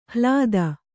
♪ hlāda